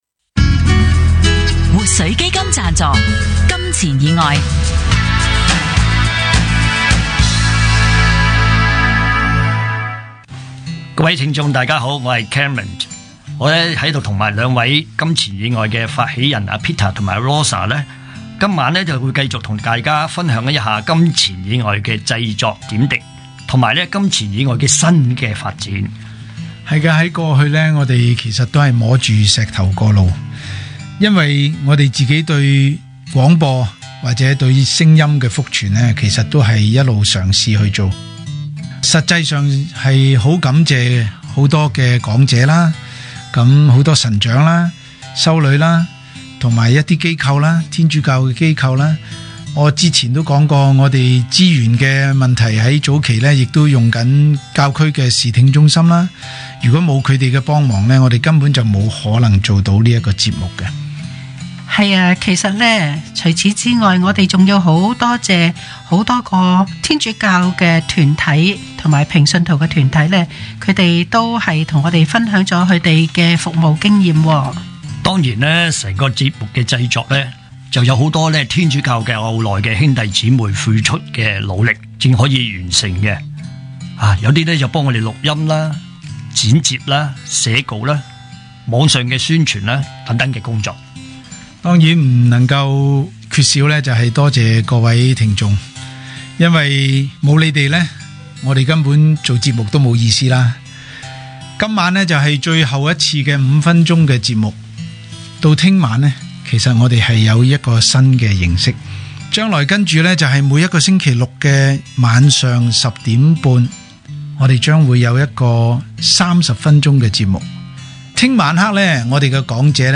電台：FM104新城財經台
天主教信仰福傳廣播《金錢以外》